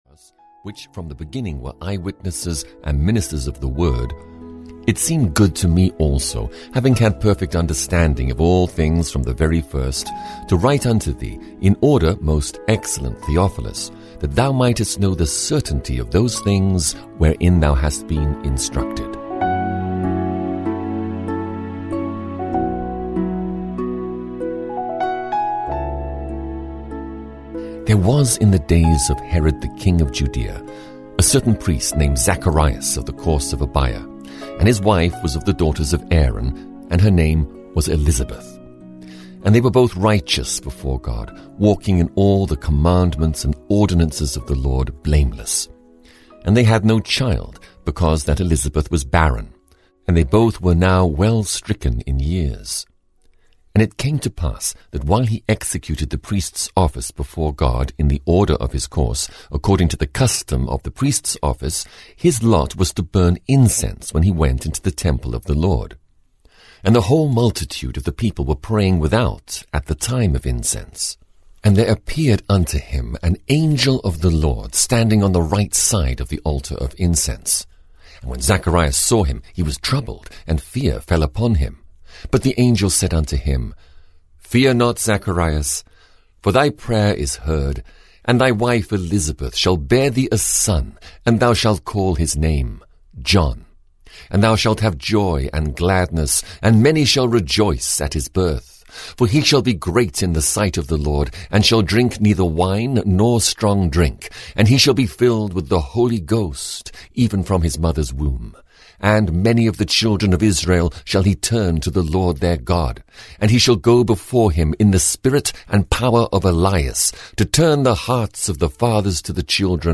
Audio knihaThe New Testament 3 - Luke (EN)